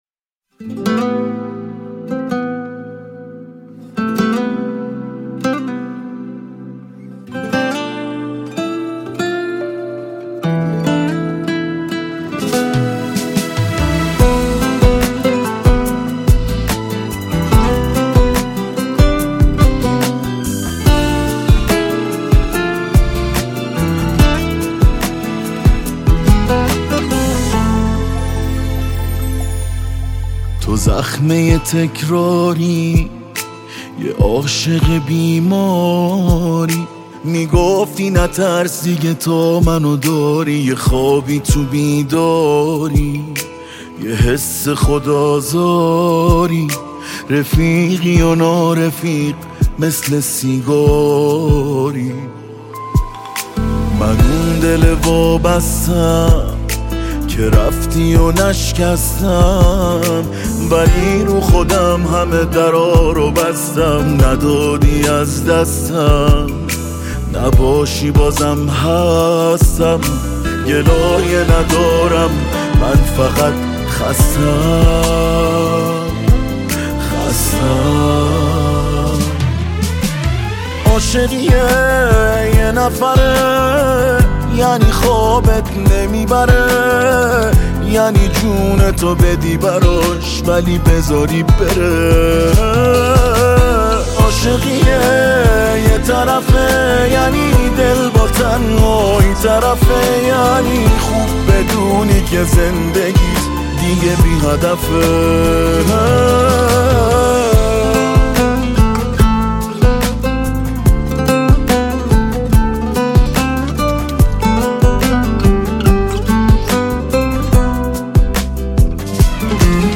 با ریتم ۴/۴